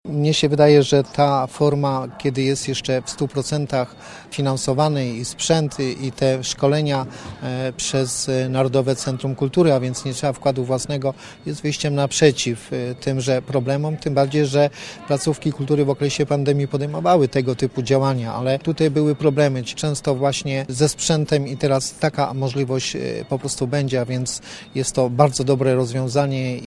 Mówi poseł Marek Kwitek: